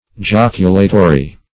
Search Result for " joculatory" : The Collaborative International Dictionary of English v.0.48: joculatory \joc"u*la*to*ry\, a. [L. joculatorius.]